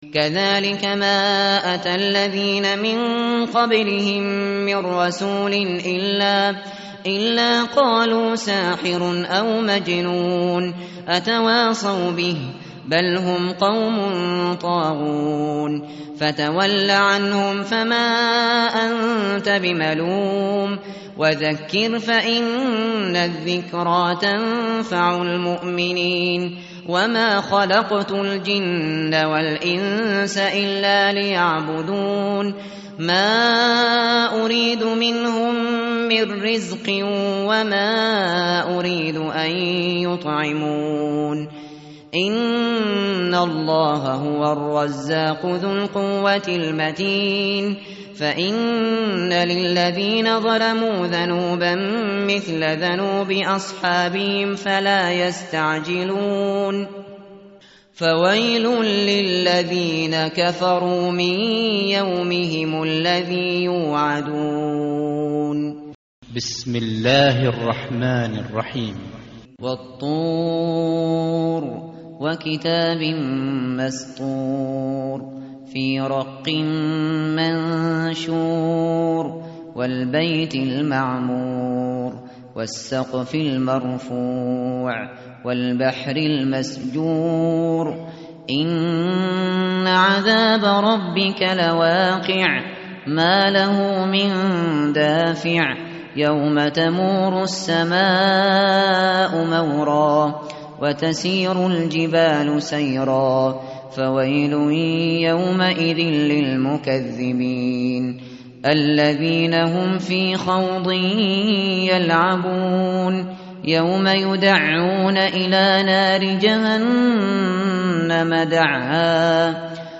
tartil_shateri_page_523.mp3